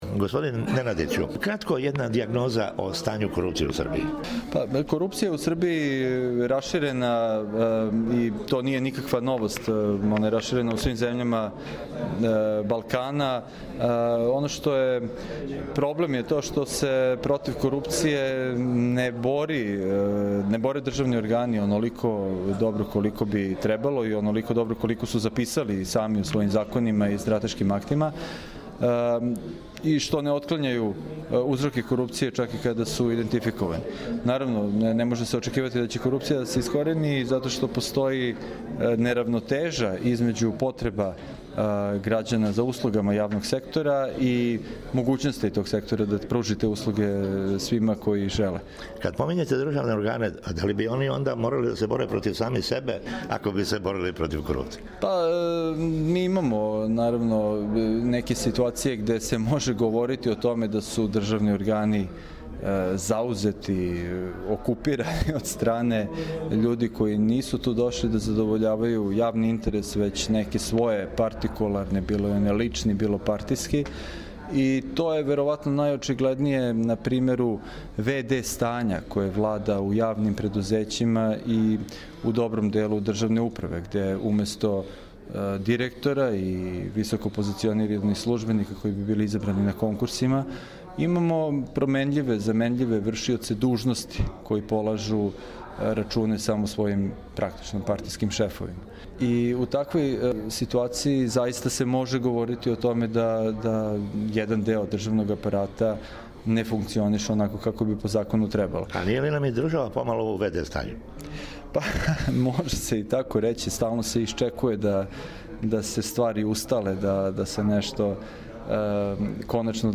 Ексклузиван интервју